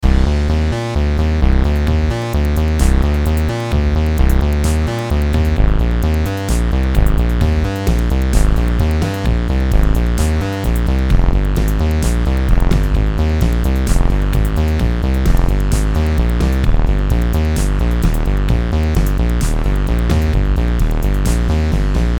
ベース音を中心に、ドラム、ギター、ピアノが融合したアップビートなサウンド。
130 BPMのエネルギッシュなリズムが、どんなシーンでも注目を集める着信音。力強いベースラインが織りなすビート。